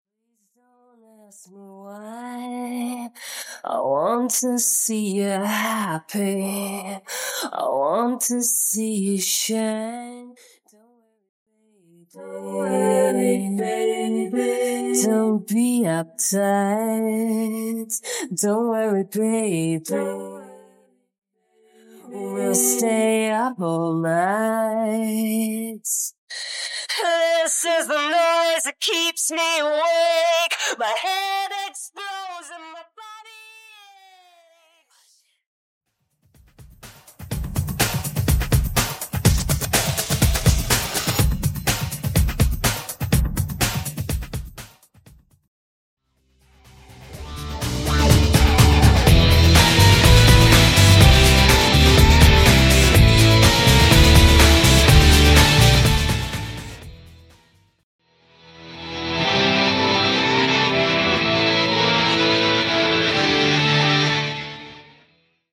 All Bassline Stem
Instrumental
Percussion & Drums Stem
Strings & Synths Stem